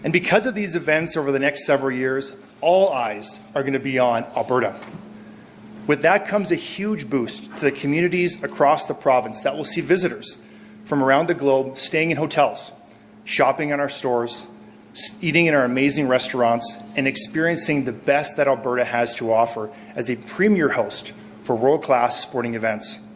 Minister Schow goes on to explain what the events will be over the next several years within Alberta for the athletes and hockey fans.